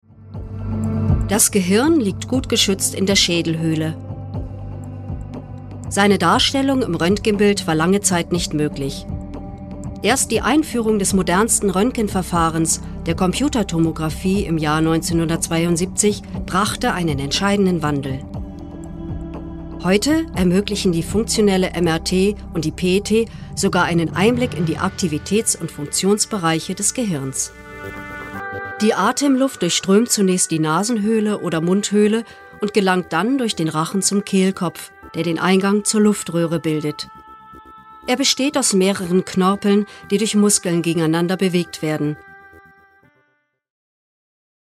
Schauspielerin, Sprecherin
Sprechprobe: Industrie (Muttersprache):